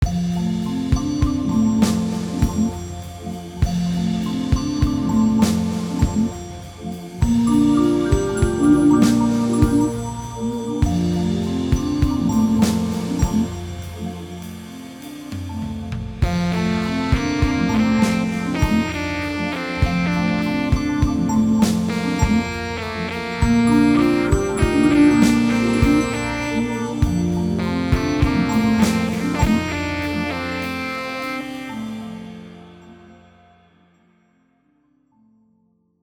Made a (very) short experiment using your plugin on the keys in this piece.
Nice and warbly.